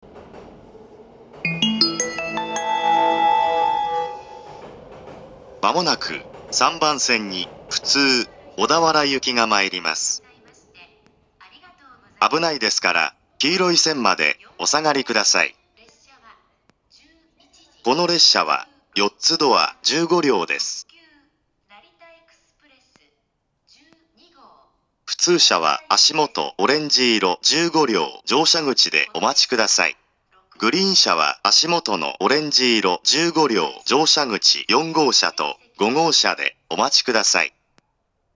２０１２年３月２７日には、自動放送が改良型ＡＴＯＳ放送に更新されました。
２０１４年１２月１日には、２０１５年３月開業の上野東京ラインに対応するため、自動放送の男声が変更されています。
３番線接近放送
totuka3bansen-sekkin2.mp3